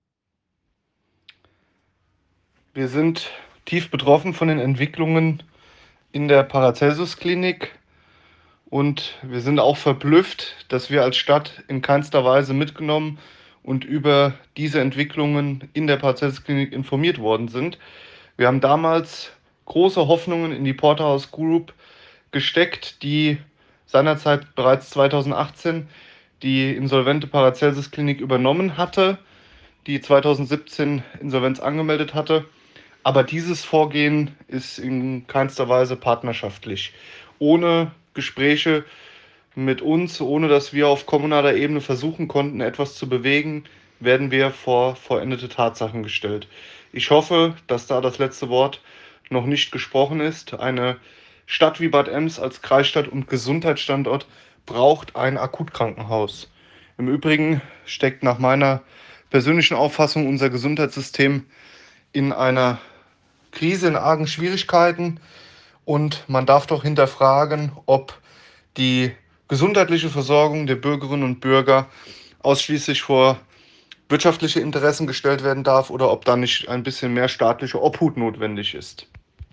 Der Bad Emser Stadtbürgermeister Oliver Krügel zeigt sich in einer ersten Stellungnahme bestürzt, aber auch enttäuscht über die Informationsstrategie des Klinikbetreibers. Er fordert ein Umdenken in der Gesundheitspolitik.
Stadtbuergermeister-Oliver-Kruegel-zur-Schliessung-der-Paracelsus-Klinik.ogg